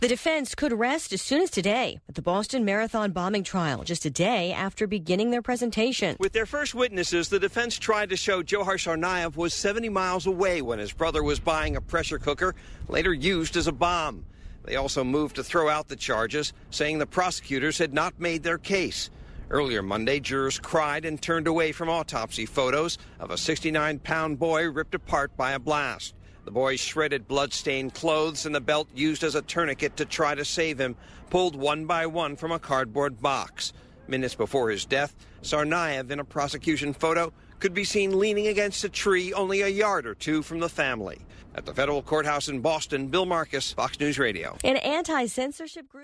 9AM-tuesday-newscast.mp3